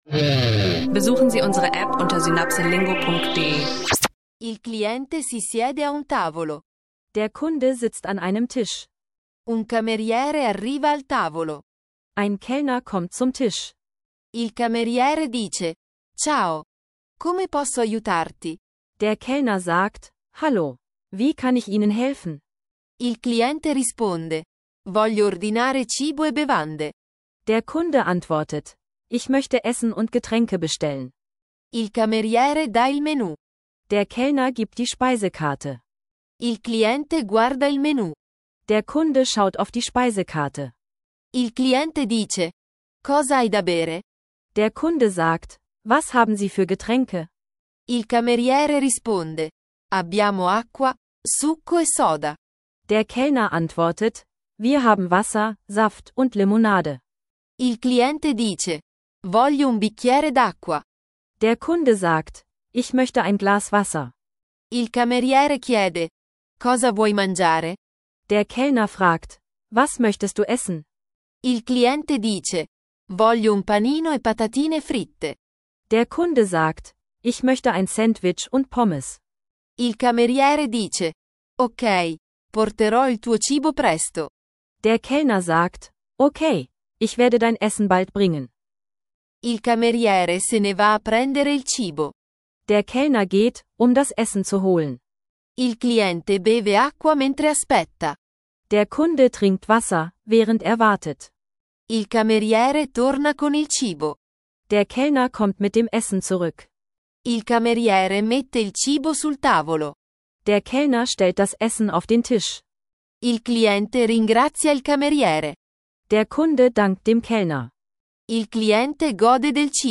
In dieser Folge übst du Italienisch lernen mit Alltagssituationen: Bestellen im Restaurant, einfache Dialoge zwischen Kunde und Kellner und passende Vokabeln. Perfekt für Italienisch lernen Podcast-Hörer, die ihr Italienisch für Anfänger oder unterwegs verbessern möchten.